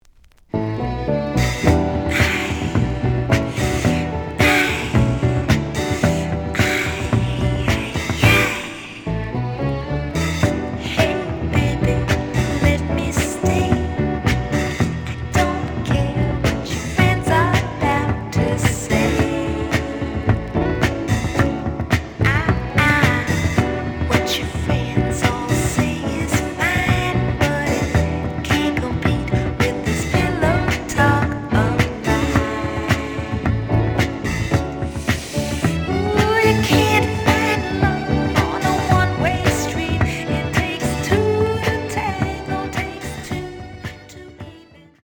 The audio sample is recorded from the actual item.
●Genre: Soul, 70's Soul
Slight sound cracking on B side.